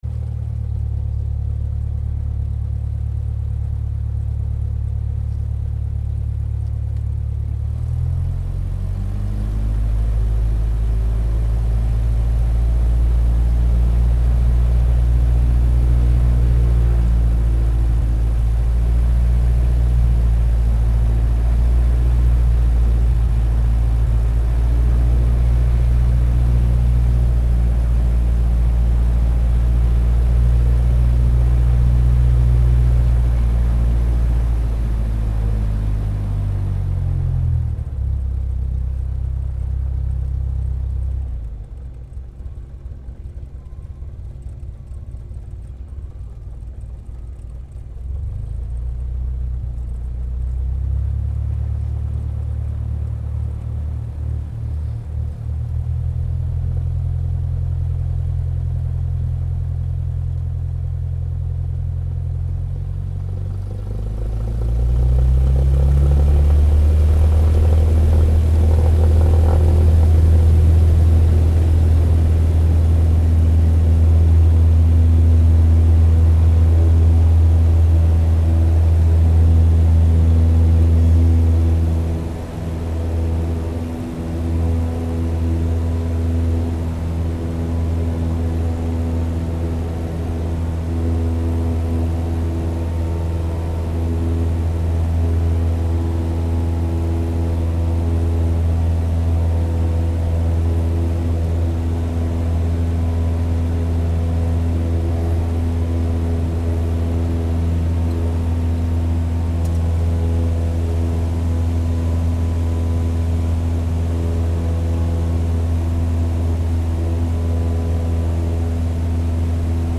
Гул взлета винтового самолета